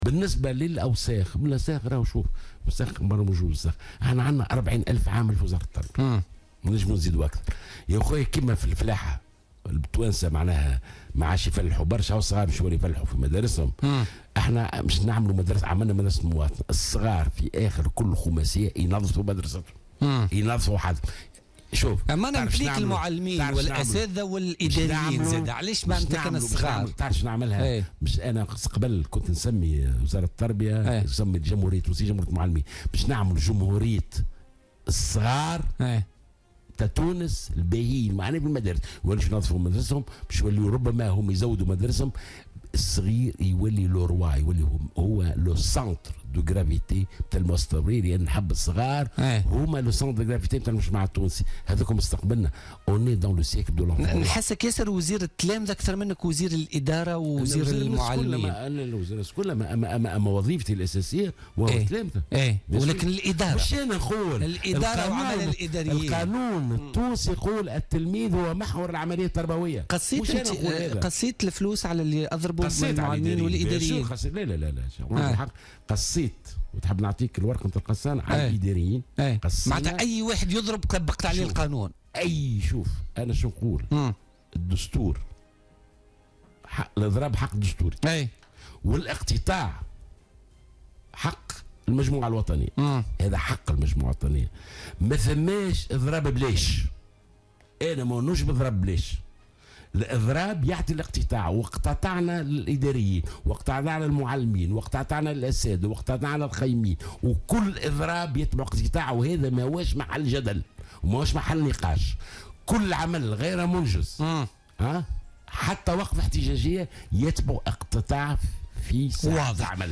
أعلن وزير التربية ناجي جلول ضيف برنامج بوليتكا لليوم الجمعة 11 نوفمبر 2016 على أمواج الجوهرة أف أم عن انطلاق حملات تنظيف في عدة مؤسسات تربوية سيقوم بها التلاميذ كل 5 أشهر.